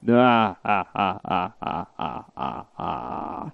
evil-laugh.mp3